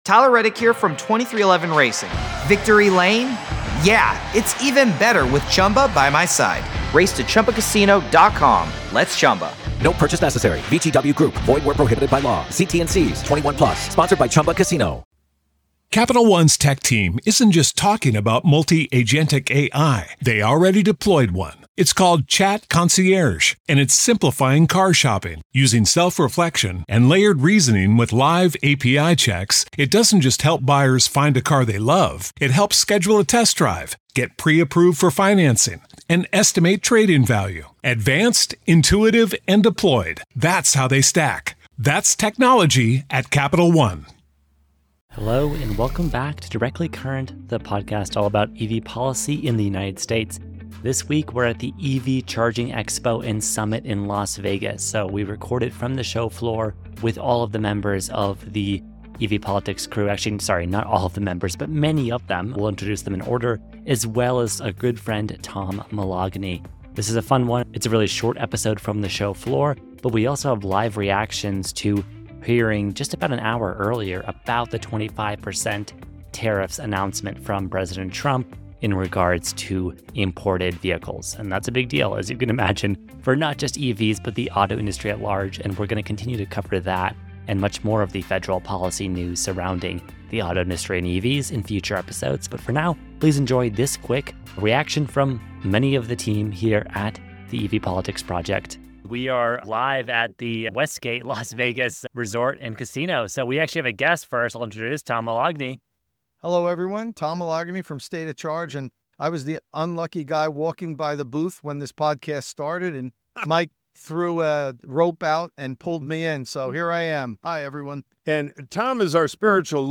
Live Crew Show From EVCS in Vegas